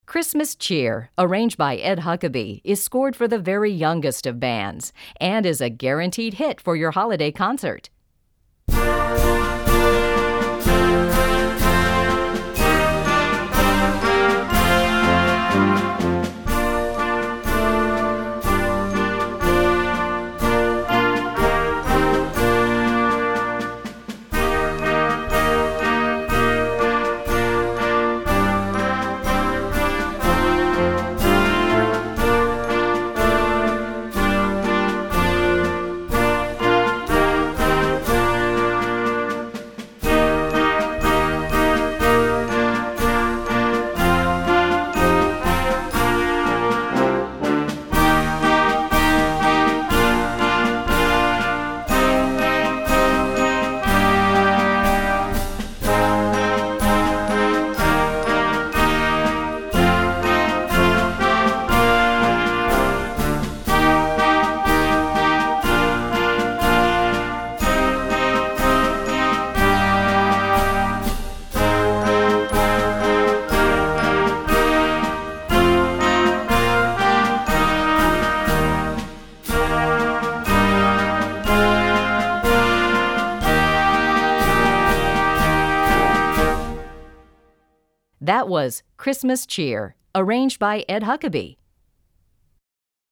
Besetzung: Blasorchester
An entertaining holiday medley at the easiest grade level.